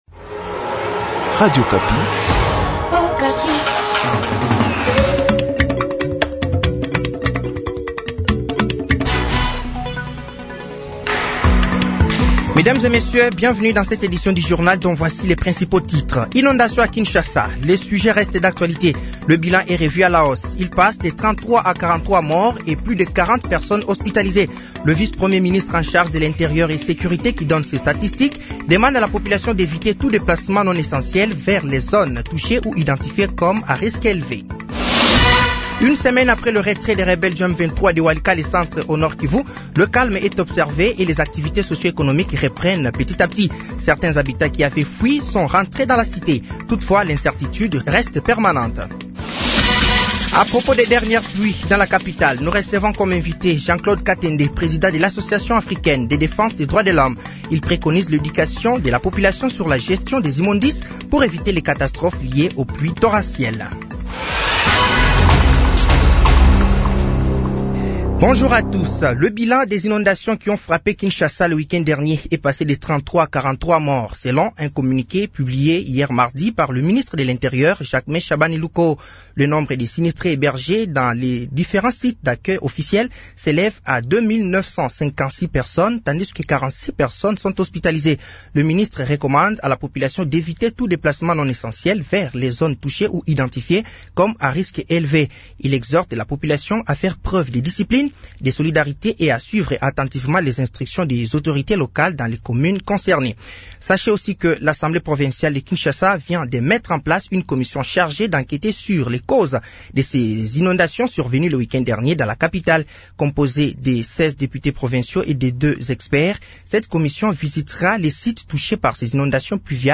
Journal midi
Journal français de 12h de ce mercredi 09 avril 2025